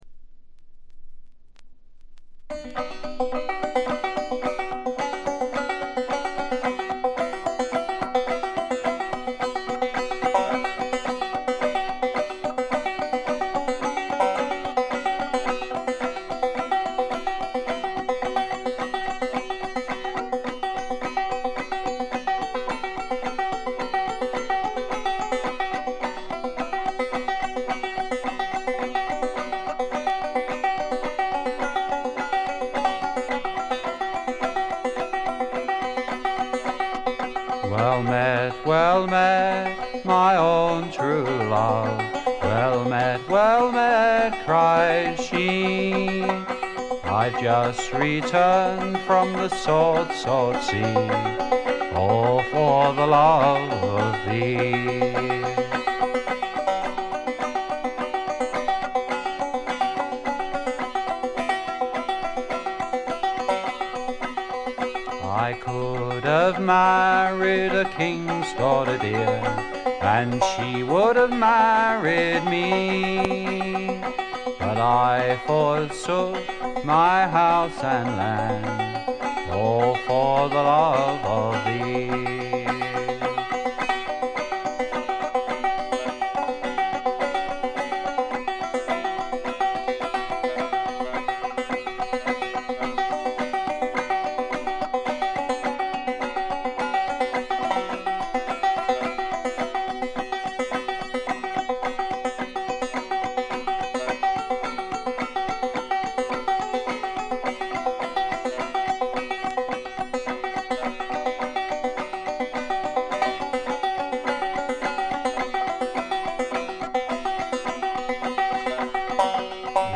基本はバンジョーをバックにした弾き語りで、訥々とした語り口が染みる作品です。
試聴曲は現品からの取り込み音源です。